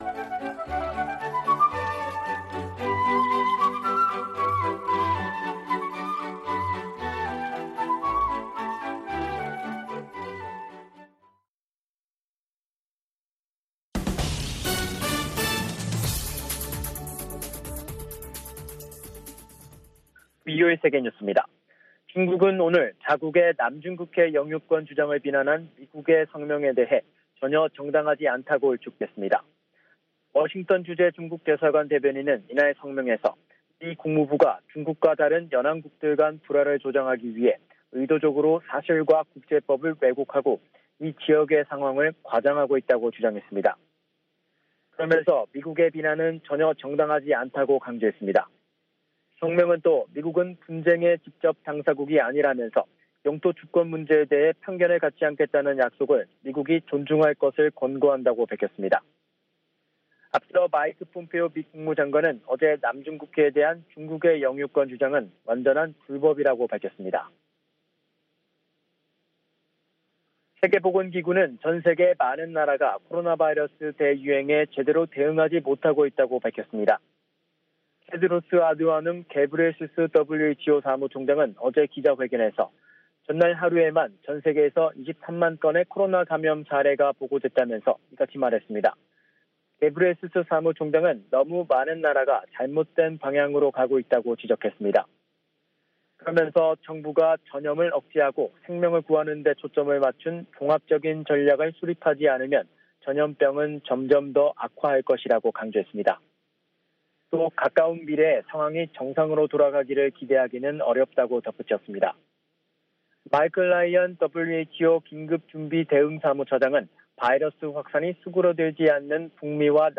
VOA 한국어 간판 뉴스 프로그램 '뉴스 투데이', 2부 방송입니다.